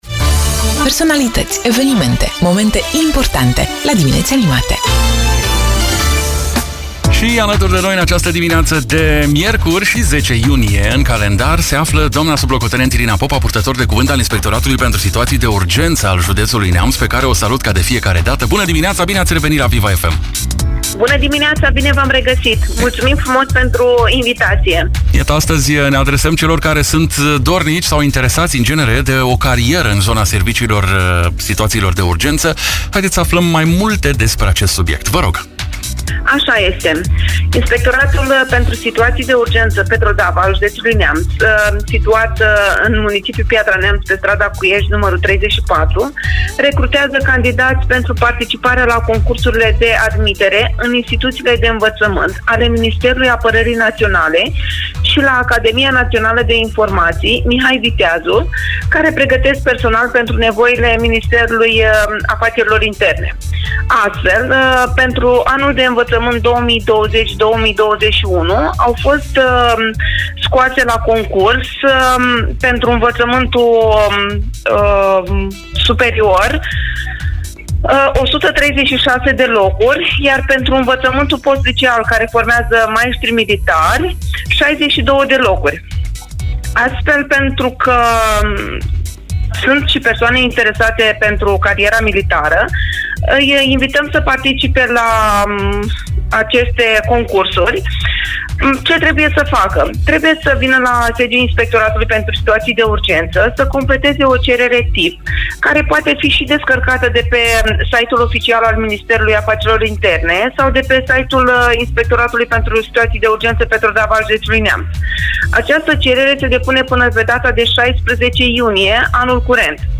Informații ne-a oferit, în direct la “Dimineți Animate” de pe Viva FM Neamț